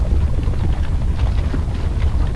flow.wav